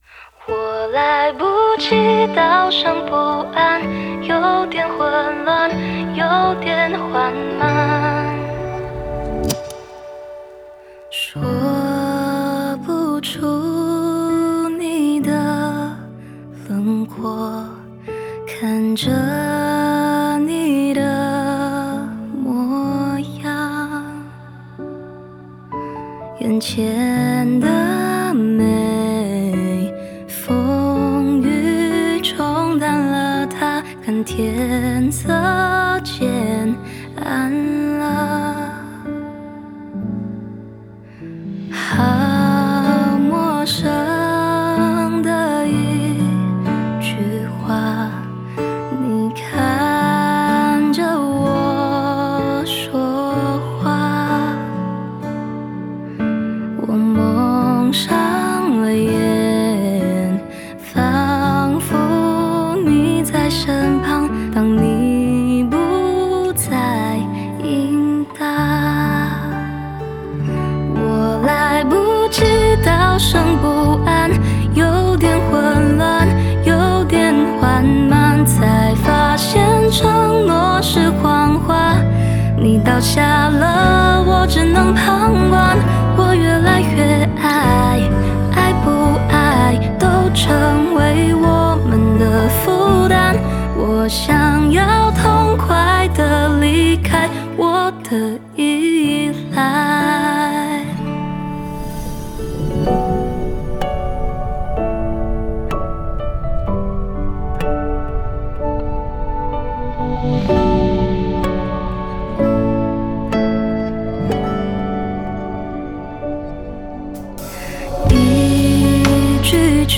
Ps：在线试听为压缩音质节选，体验无损音质请下载完整版
吉他 Guitar
和声 Background Vocals